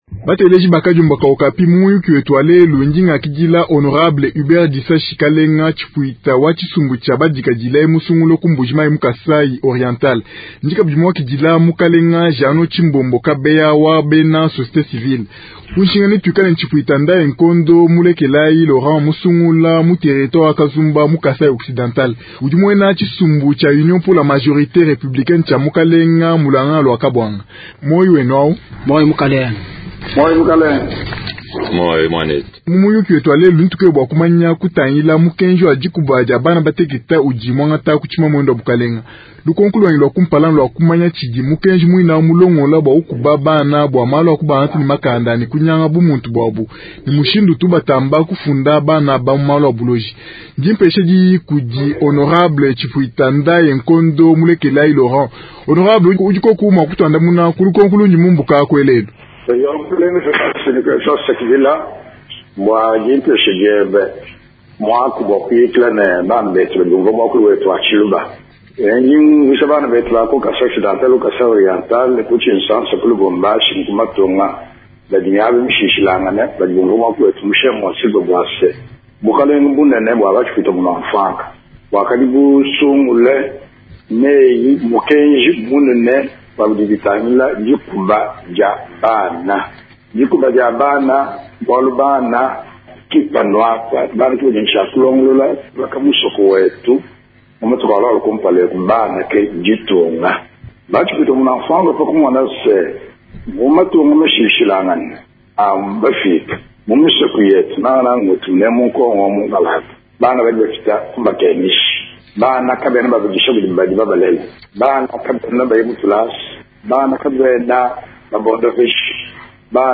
Tel est le thème central du débat en tshiluba de ce soir.